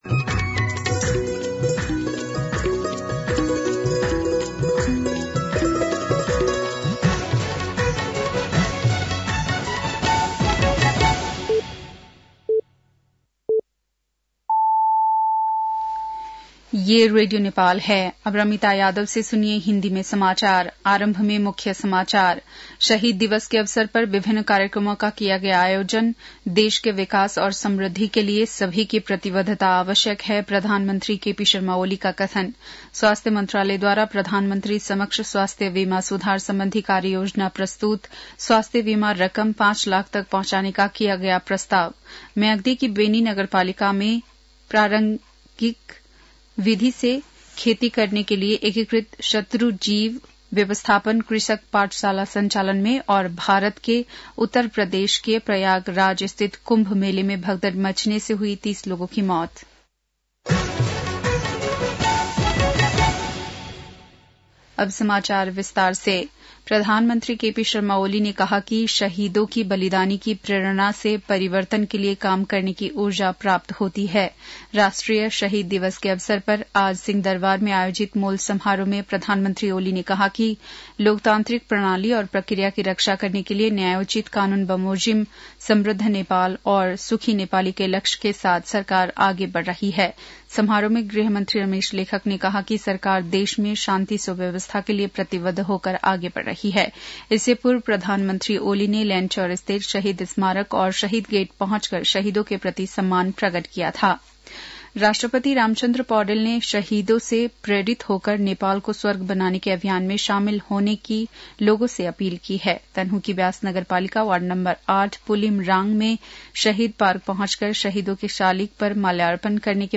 बेलुकी १० बजेको हिन्दी समाचार : १७ माघ , २०८१